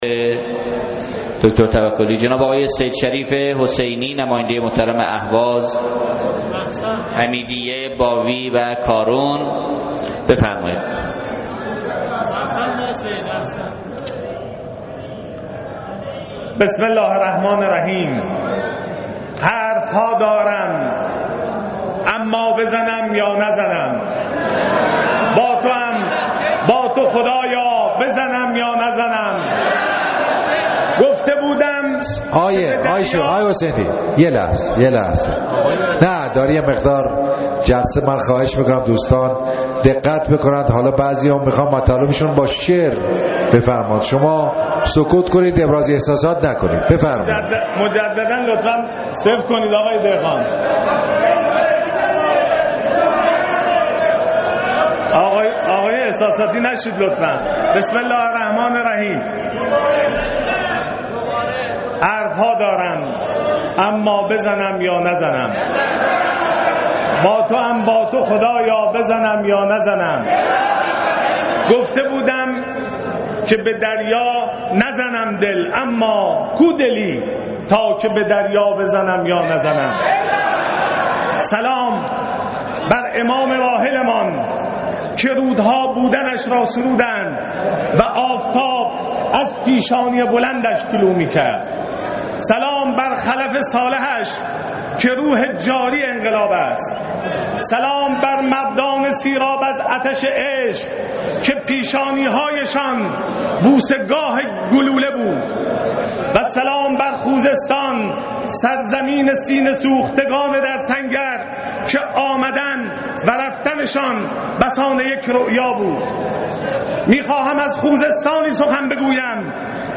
نماینده مردم اهواز در مجلس شورای اسلامی در جلسه علنی امروز نطقش را با شعری از قیصر امین‌پور آغاز کرد که واکنش نمایندگان و نایب رئیس مجلس را به دنبال داشت.
که در این حین نمایندگان مجلس یک‌صدا فریاد زدند نزن! نزن!